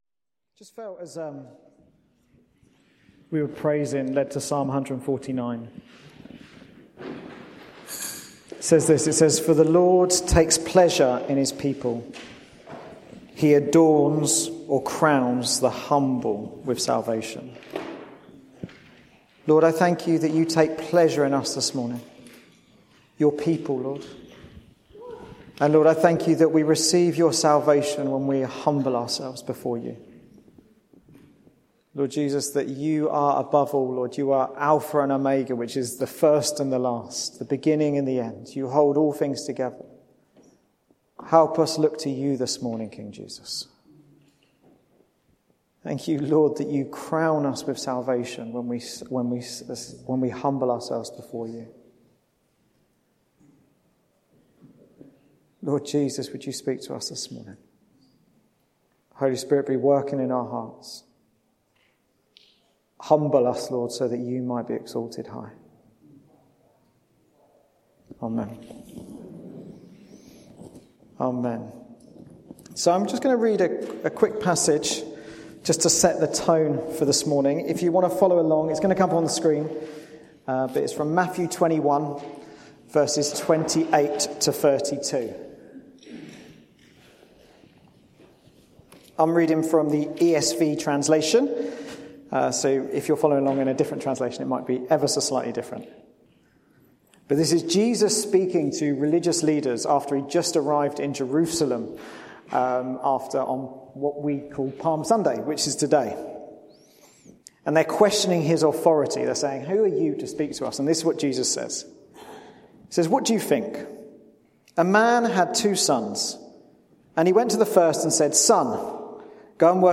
The Church in Bassett Street Podcast Archive